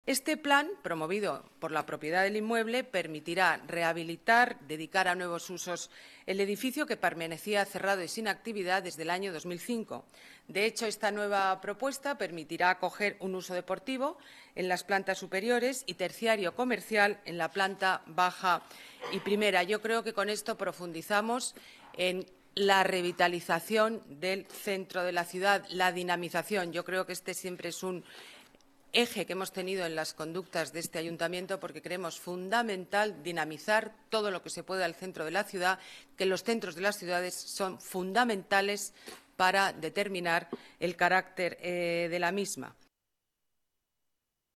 Nueva ventana:Declaraciones alcaldesa Madrid, Ana Botella: nuevos usos Cines Madrid